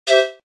th_sfx_whistle_1.ogg